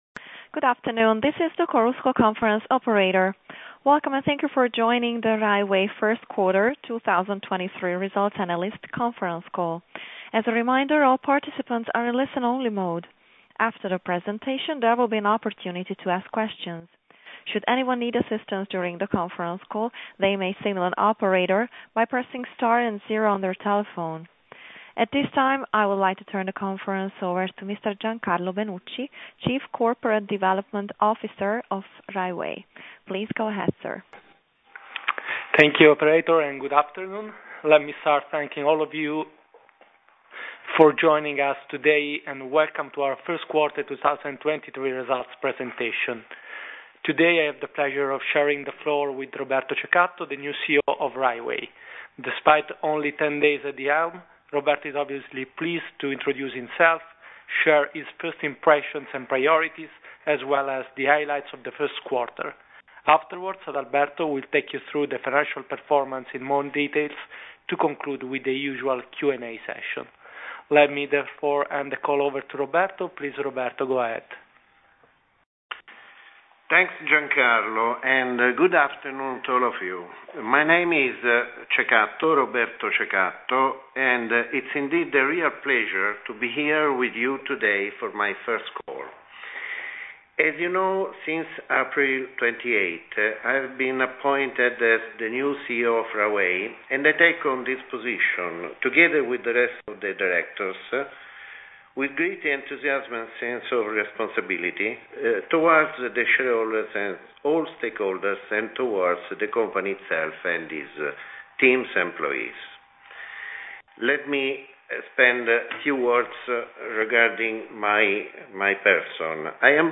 Audio mp3 Conference call Risultati 1Q2023